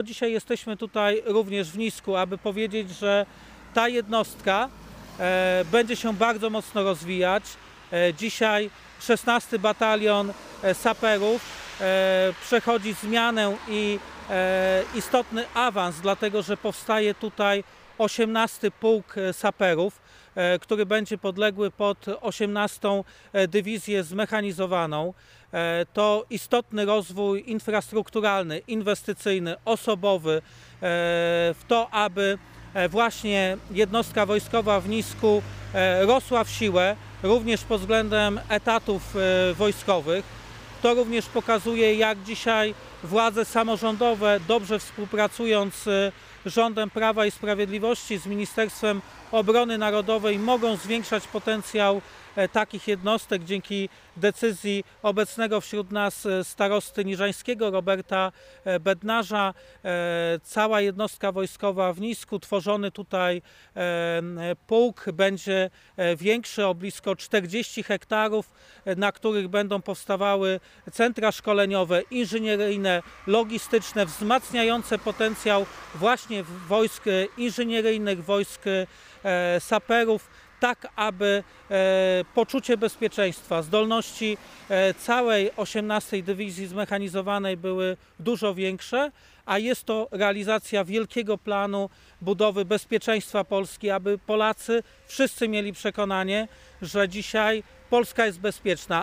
Przed jednostką wojskową w Nisku odbyła się konferencja prasowa Prawa i Sprawiedliwości poświęcona sprawom bezpieczeństwa Polski i rozwoju jednostek wojskowych w naszym Regionie.